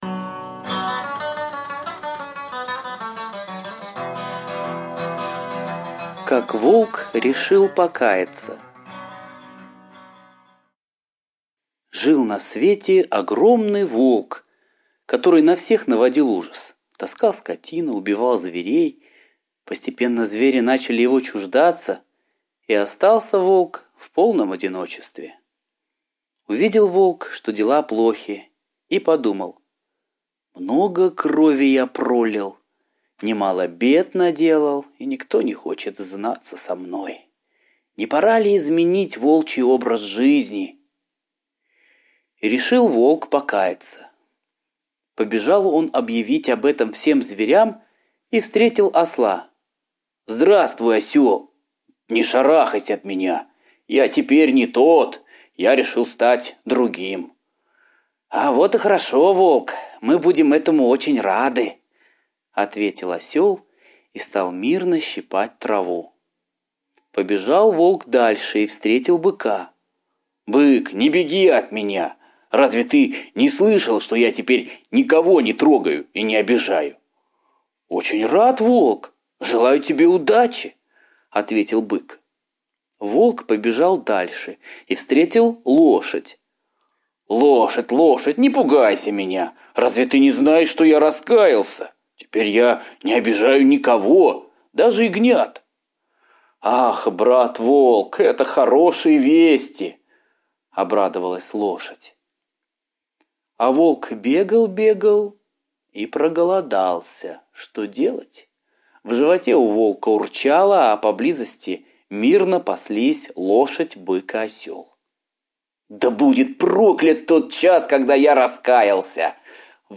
Как Волк решил покаяться - восточная аудиосказка - слушать онлайн